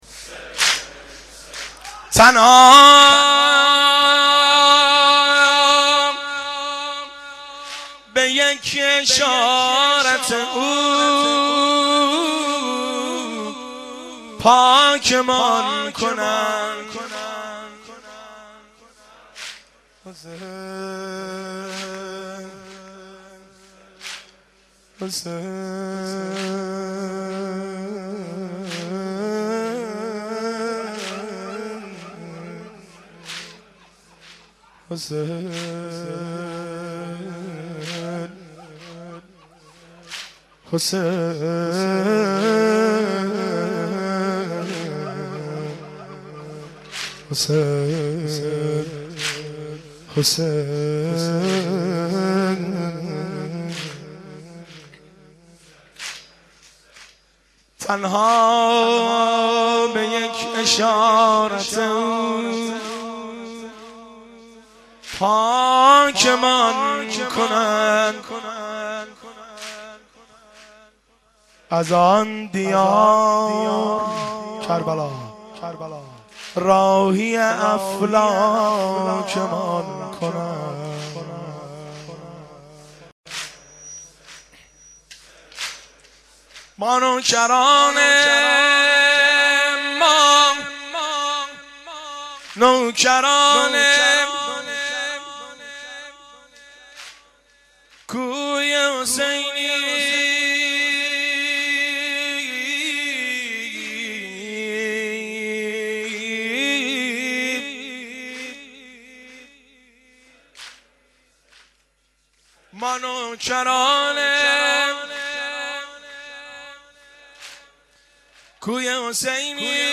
زمزمه – شب ششم محرم الحرام 1390